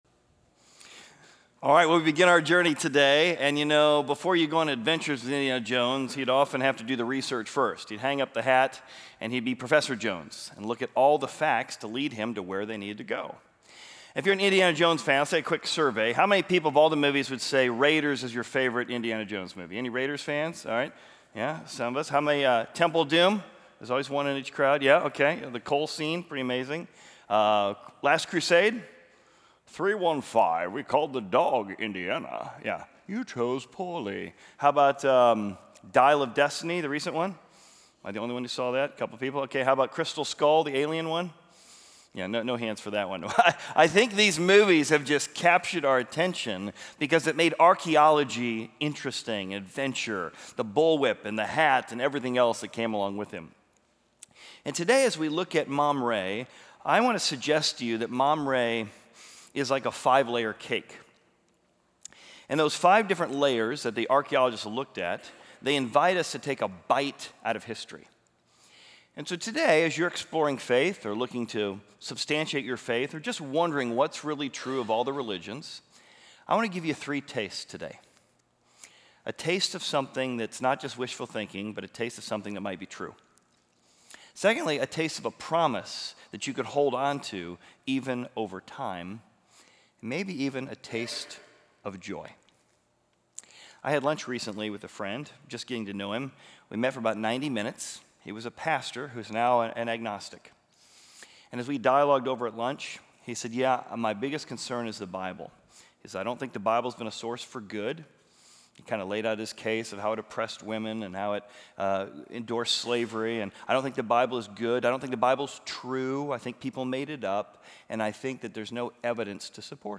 Exploring Service / Raiders of The Lost Cities / Mamre